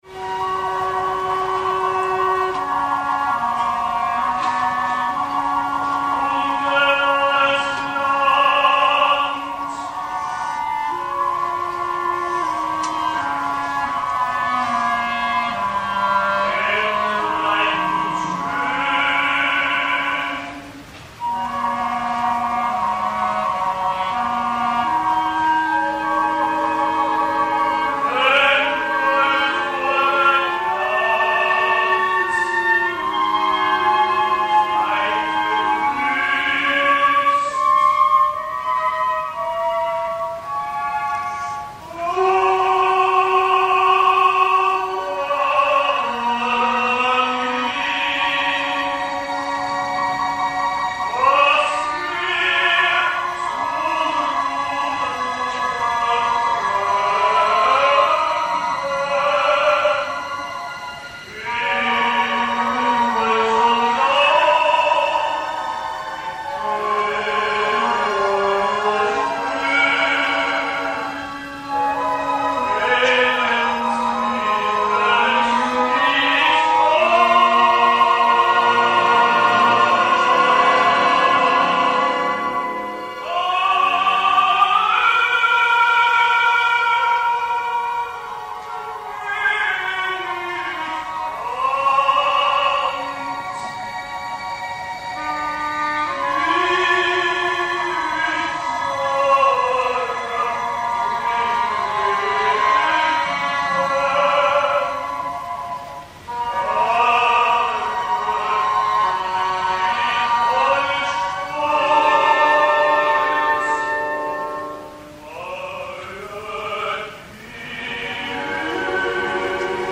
Beautiful timbre, quite captivating interpreter, so-so singer prone to pushing.